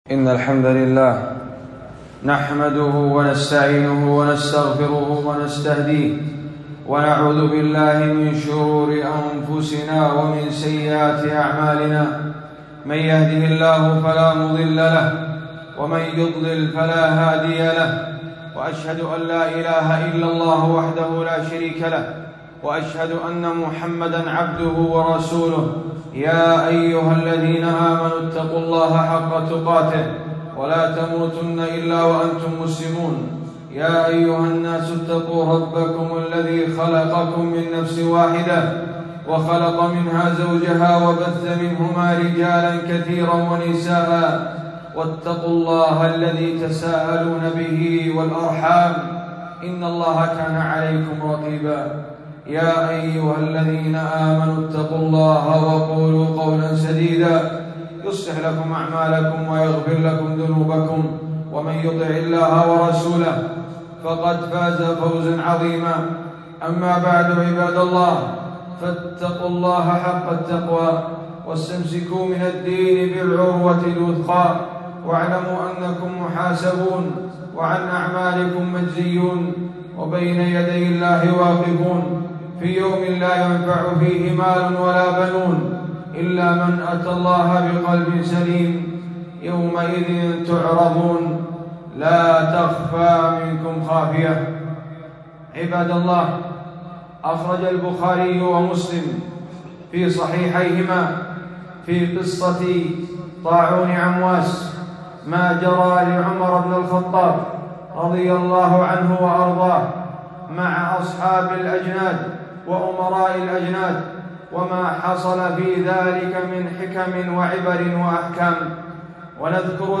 خطبة - نفر من قدر الله إلى قدر الله